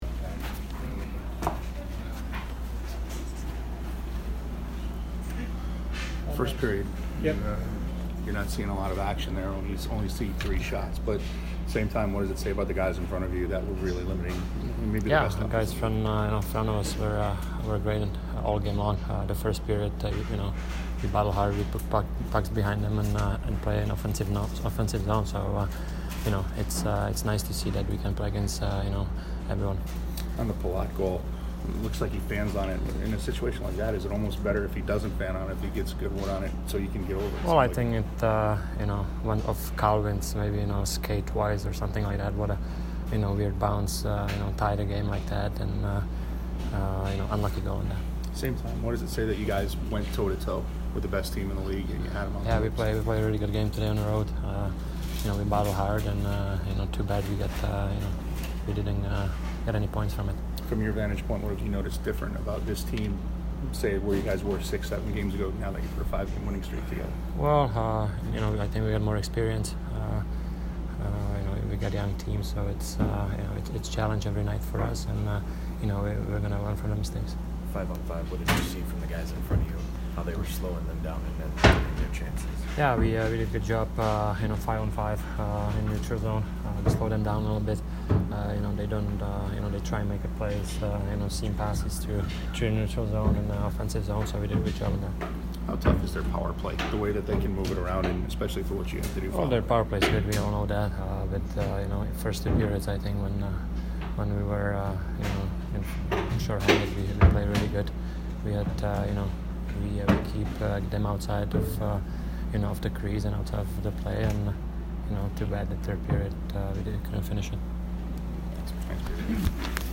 Petr Mrazek post-game 1/10